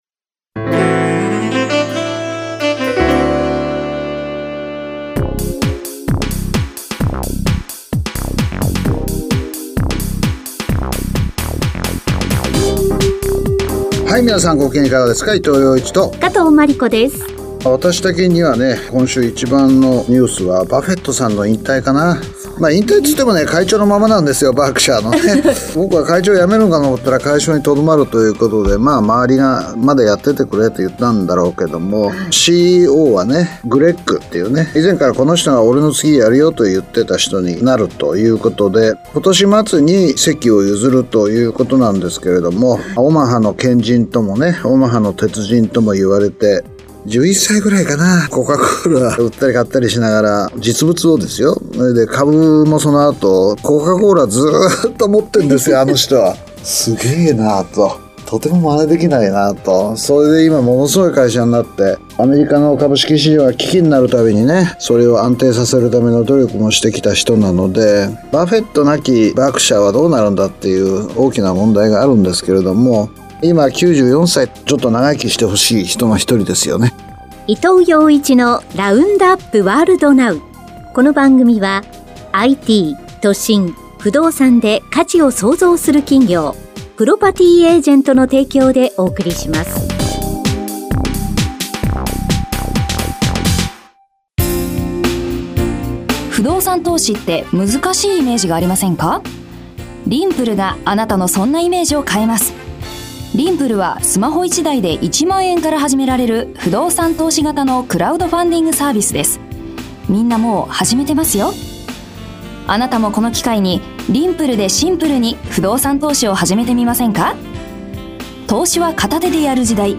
伊藤洋一氏が日本だけでなく世界中で起きた1週間の経済・社会・政治関連の出来事、指標、トピックスなどを分かりやすく解説。
… continue reading 479 epizódok # ニューストーク # ニュース # ビジネスニュース # NIKKEI RADIO BROADCASTING CORPORATION